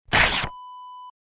Paper Rip / Tear Wav Sound Effect #18
Description: The sound of a quick paper rip
Properties: 48.000 kHz 16-bit Stereo
A beep sound is embedded in the audio preview file but it is not present in the high resolution downloadable wav file.
paper-rip-preview-18.mp3